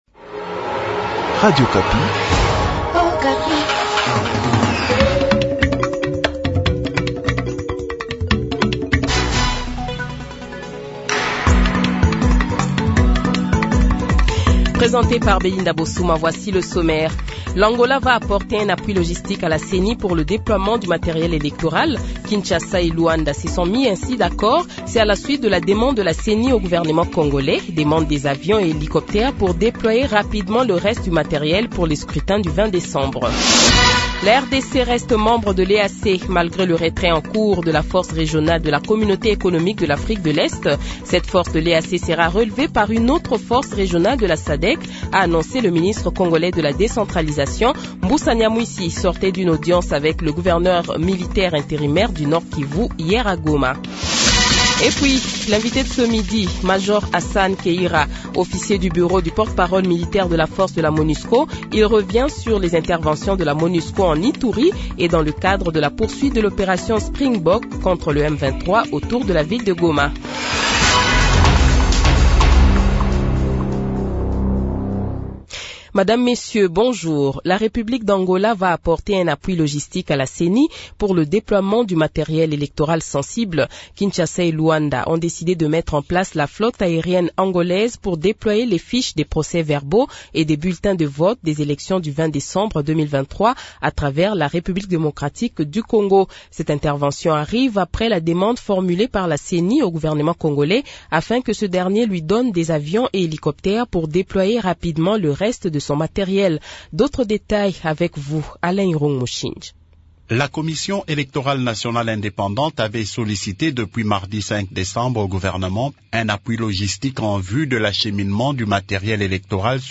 Le Journal de 12h, 09 Decembre 2023